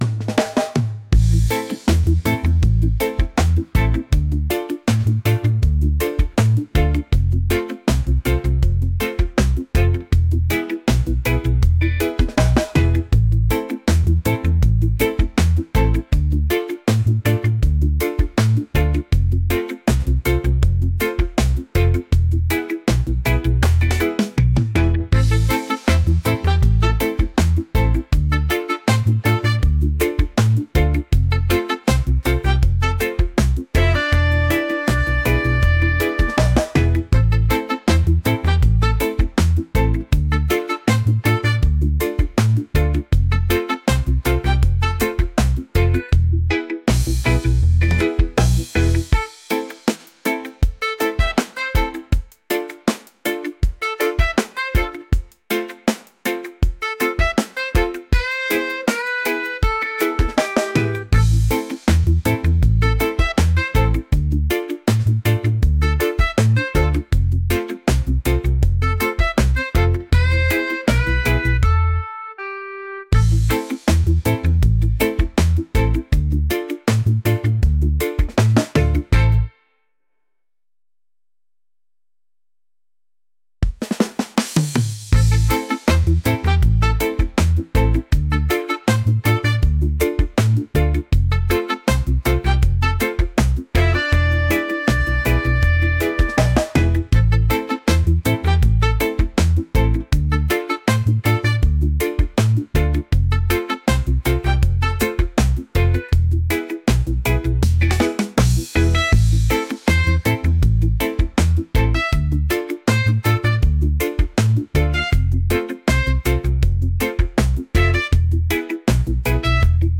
reggae | soulful